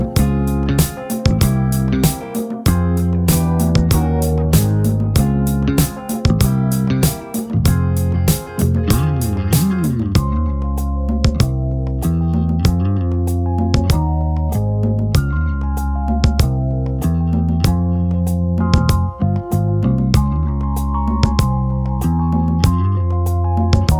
no sax Soul / Motown 3:54 Buy £1.50